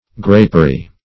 Grapery \Grap"er*y\, n. A building or inclosure used for the cultivation of grapes.